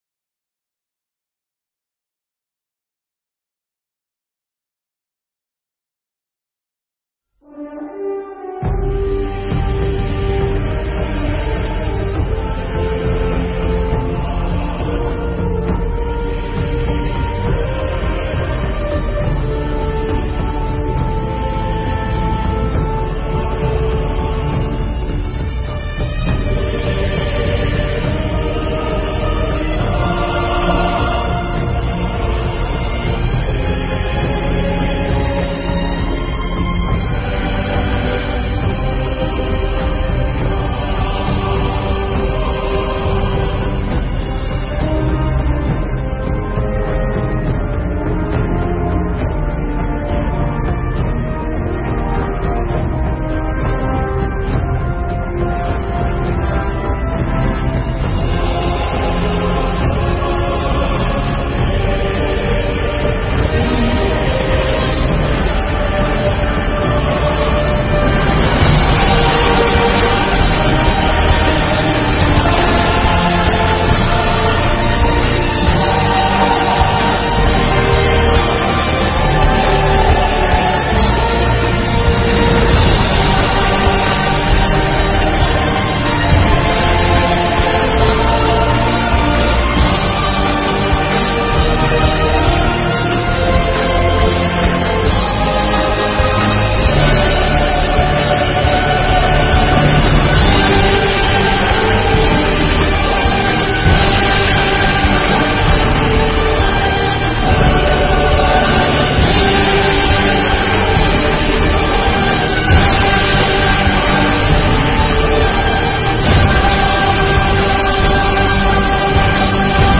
Pháp âm Khi Nào Tận Thế